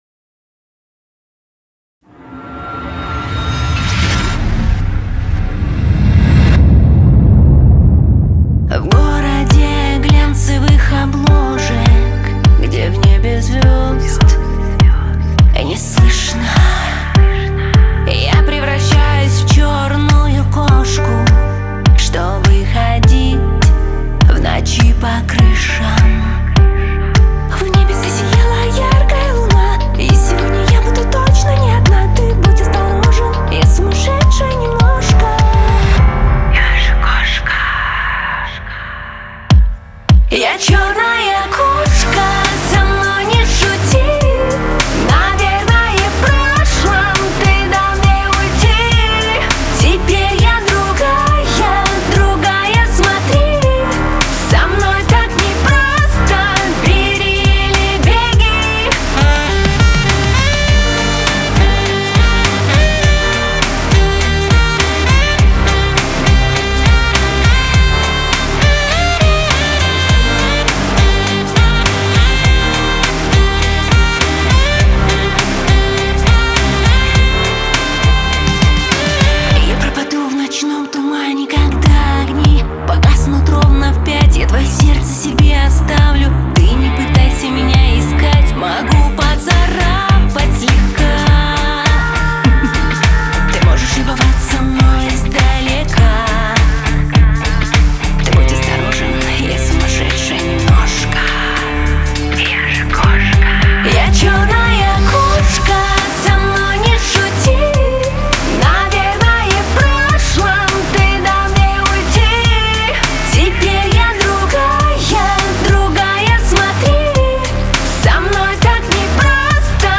Лирика , эстрада , весёлая музыка